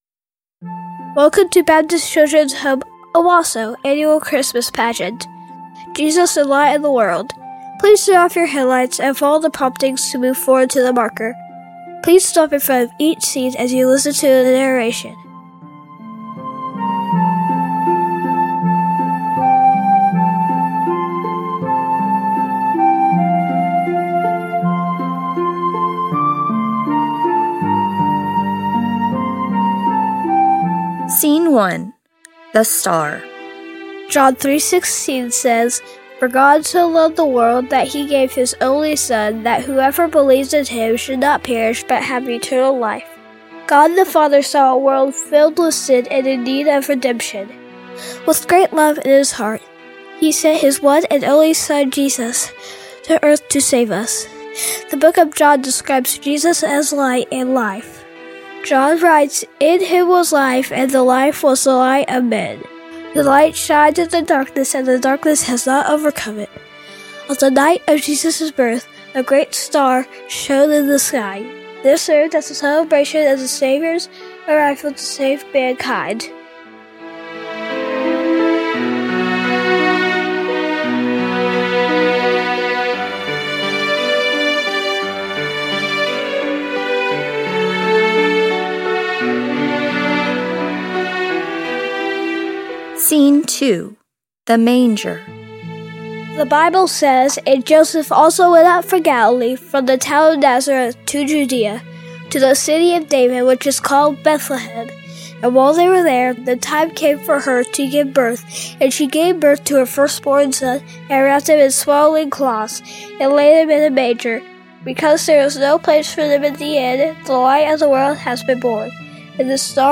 Christmas Pageant Soundtrack Pageant Soundtrack Make a Donation We hope you'll make plans to join us this year for our annual drive-through Christmas Pageant!